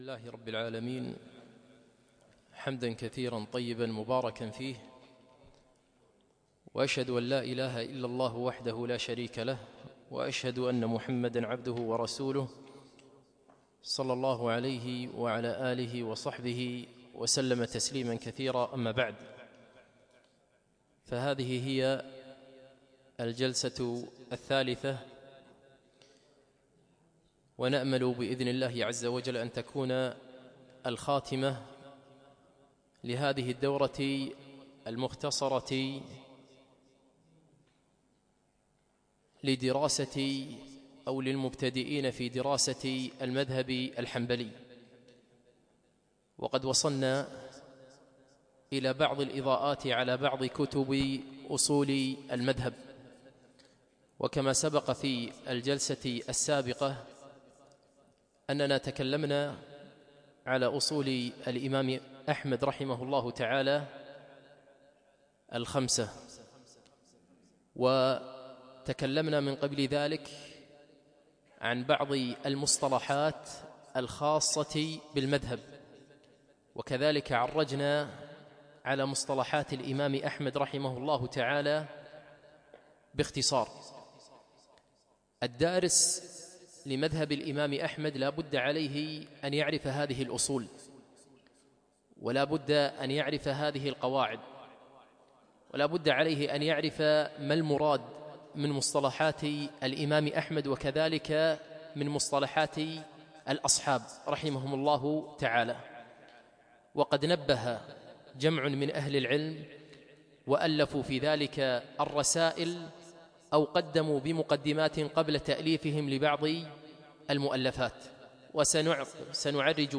يوم الثلاثاء 10 جمادى الاول 1438 الموافق 7 2 2017 في مسجد العلاء بن عقبة الفردوس